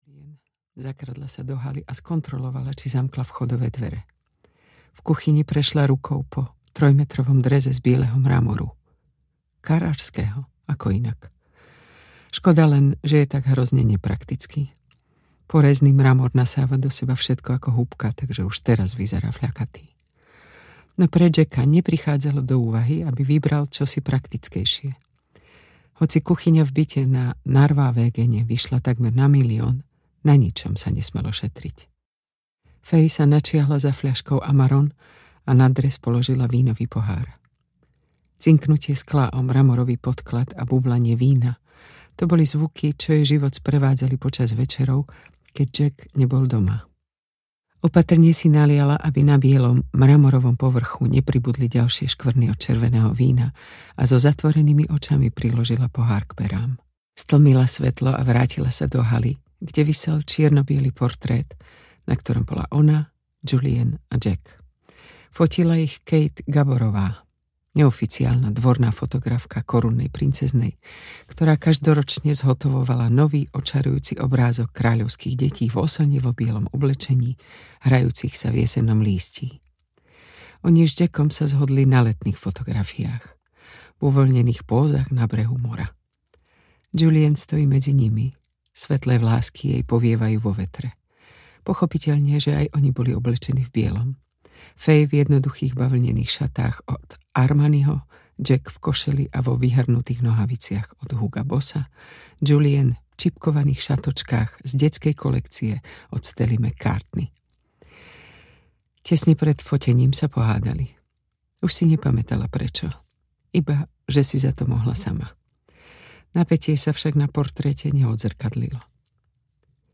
Zlatá klietka audiokniha
Ukázka z knihy